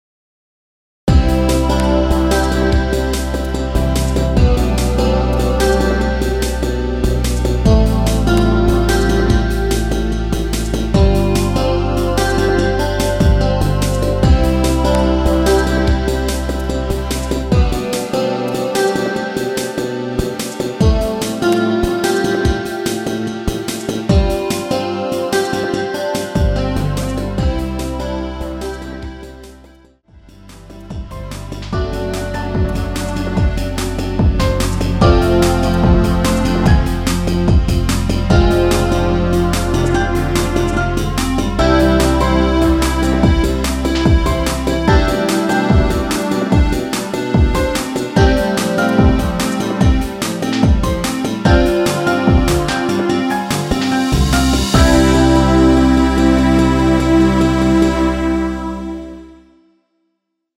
엔딩이 길고 페이드 아웃이라서 노래 끝나고 8마디 진행후 엔딩을 만들었습니다.
원키에서(-1)내린 MR입니다.